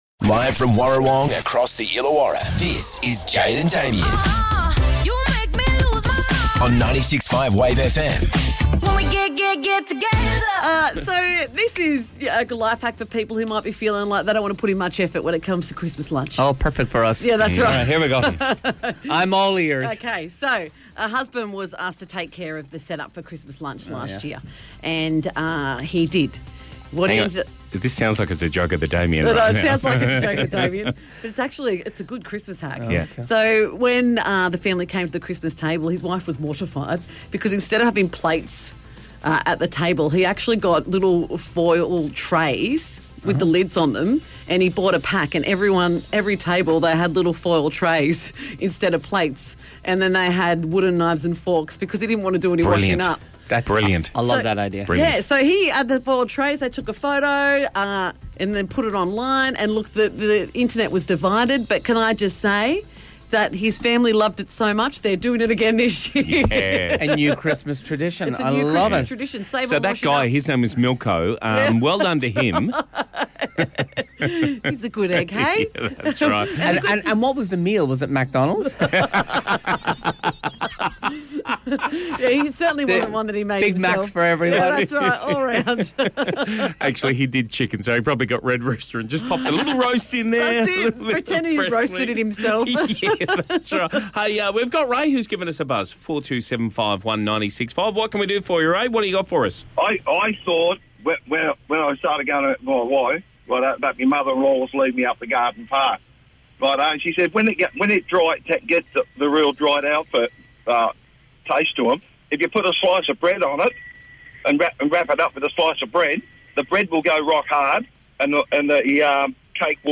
sing an Xmas medley, Worldwide Weird